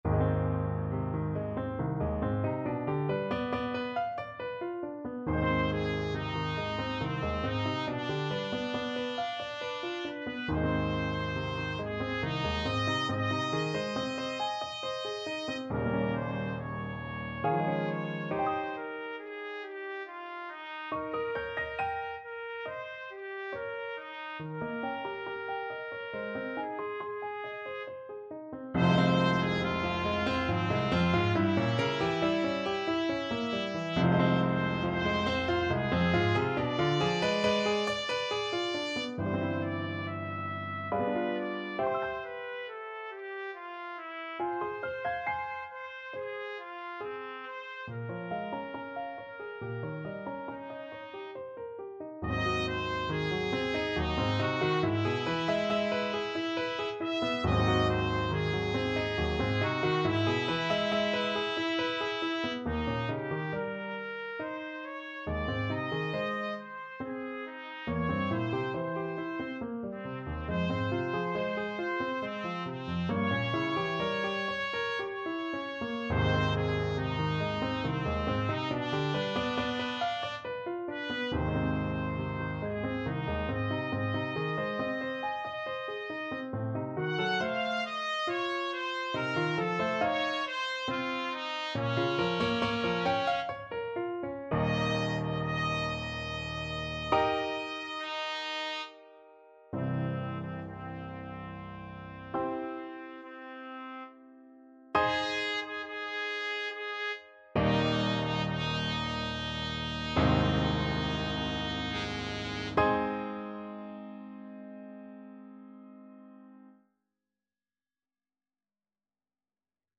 Classical Massenet, Jules Voix supreme Trumpet version
Trumpet
Ab major (Sounding Pitch) Bb major (Trumpet in Bb) (View more Ab major Music for Trumpet )
3/4 (View more 3/4 Music)
~ = 69 Large, soutenu
Ab4-F#6
Classical (View more Classical Trumpet Music)